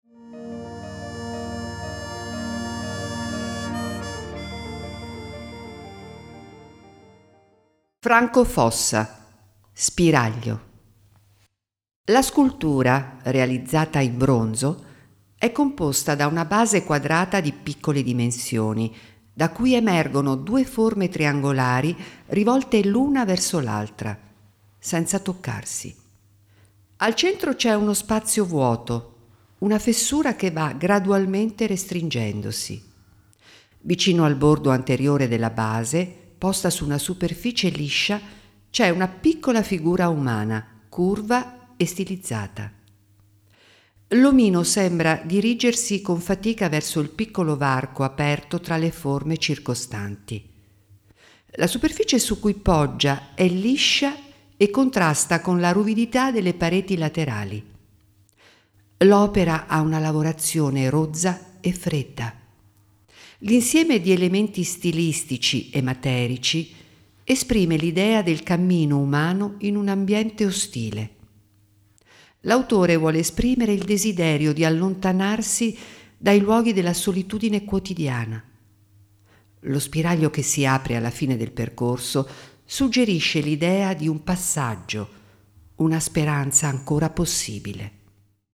Audiodescrizione dell’opera
franco-fossa-spiraglio-audiodescrizione.mp3